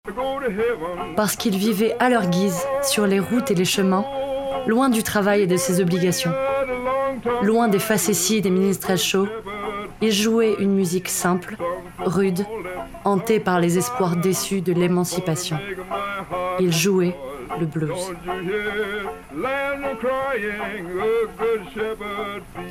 IMDA - Voix Off - Black Music " Des chaines de fer aux chaines en or"